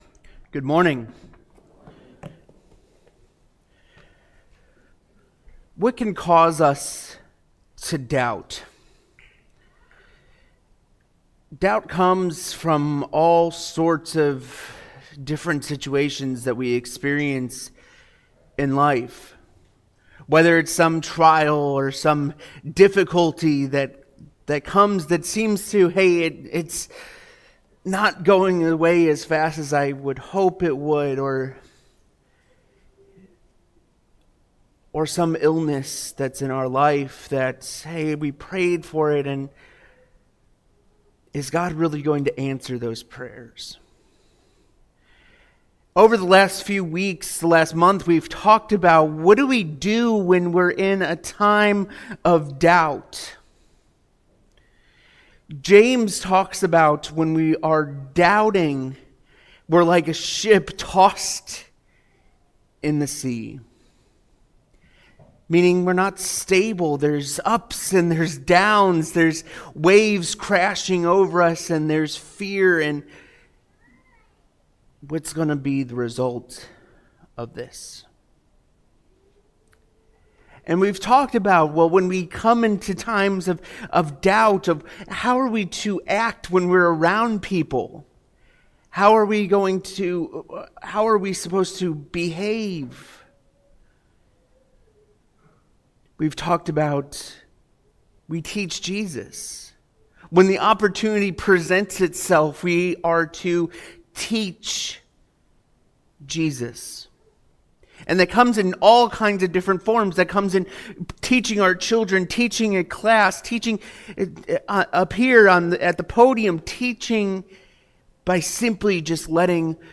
Sunday Sermons When in Doubt...